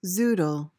PRONUNCIATION: (ZOO-duhl) MEANING: noun: A thin strip of a vegetable, typically zucchini, prepared like pasta.